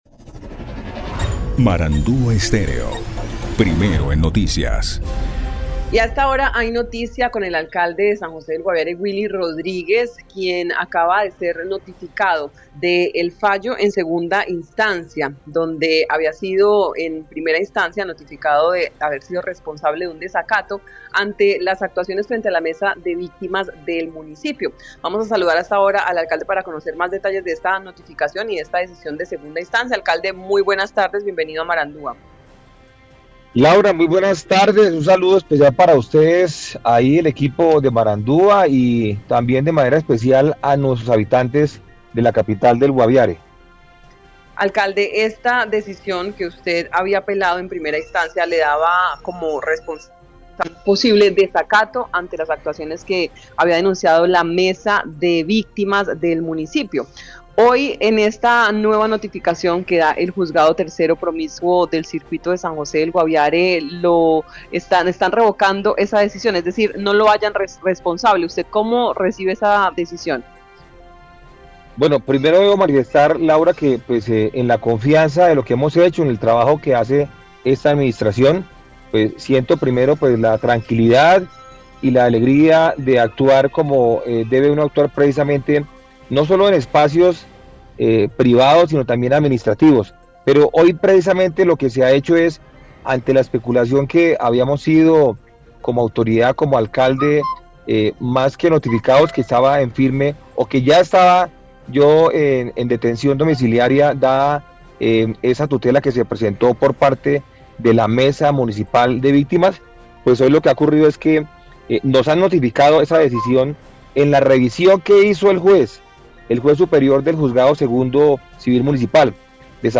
En entrevista con Marandua, Rodríguez se mostró satisfecho con esta decisión y señaló que con este fallo en segunda instancia queda claro que su actuación en respuesta a la información solicitada por los miembros de la Mesa municipal de Víctimas fue la correcta y que no tendrá que dar más respuestas.